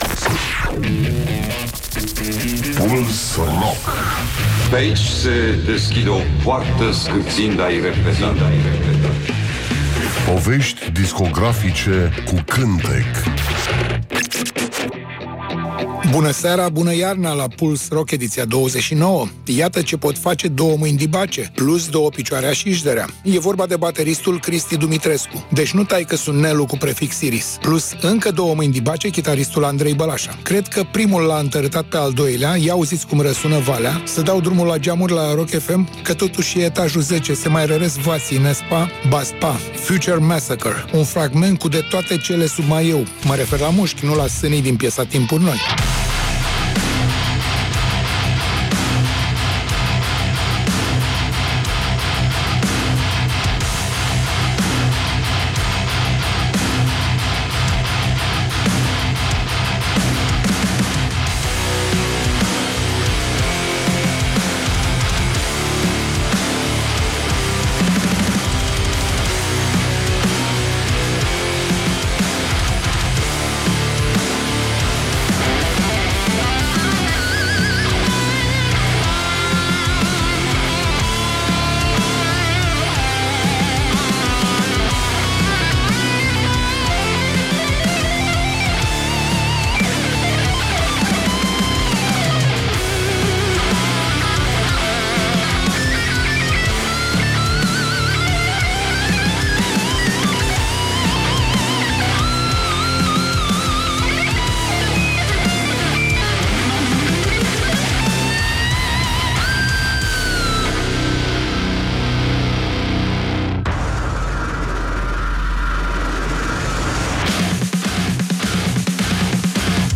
Emisiunea se numește Puls Rock și jonglează cu artiștii noștri rock, folk, uneori chiar jazz.